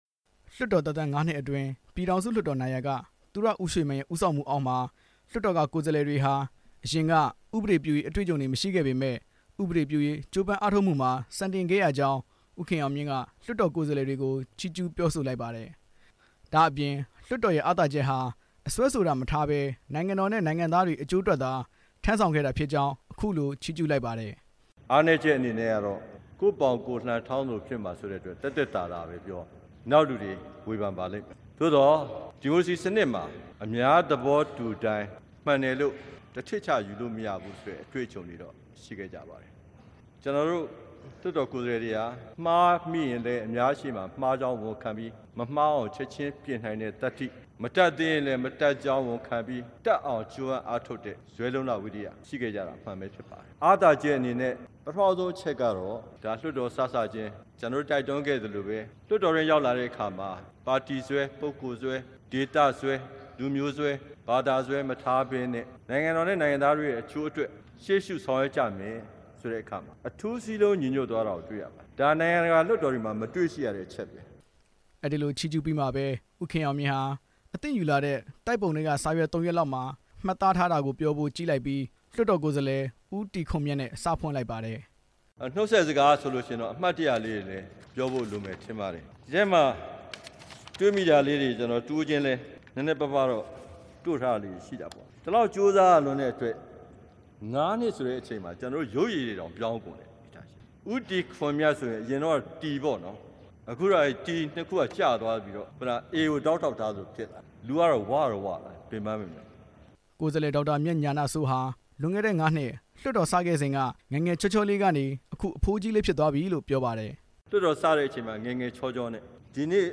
ဒီကနေ့ ပြည်ထောင်စုလွှတ်တော်နောက်ဆုံးနေ့မှာ အမျိုးသားလွှတ်တော် ဥက္ကဌ ဦးခင်အောင်မြင့်က နှုတ်ဆက်စကားပြောရာမှာ လွှတ်တော်ရဲ့ အားနည်းချက် အားသာချက်တွေကို ရှင်းပြခဲ့ပြီး ဒေါ်အောင်ဆန်းစုကြည် အပါအဝင် လွှတ်တော်ကိုယ်စားလှယ်တချို့ရဲ့ ထူးခြားချက်တွေကို ဟာသနှောပြီး ပြောခဲ့ပါတယ်။